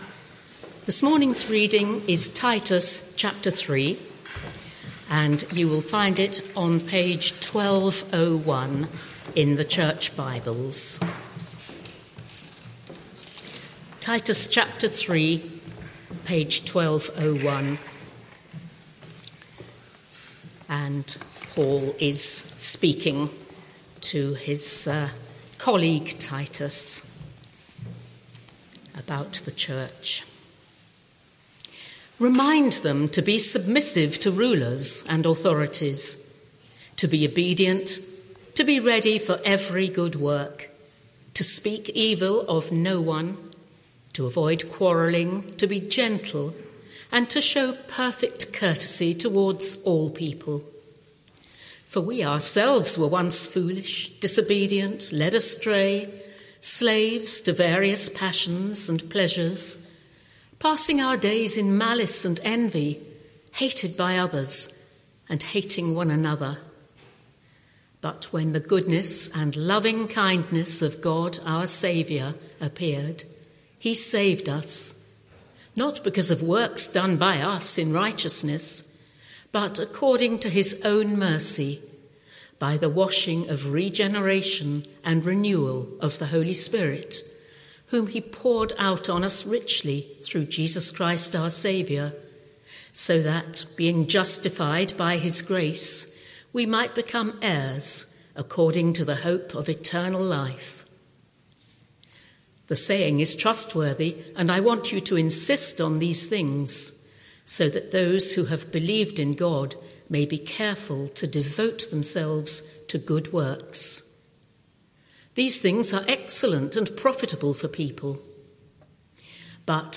Media for Morning Meeting on Sun 20th Jul 2025 10:30 Speaker
Sermon Search media library...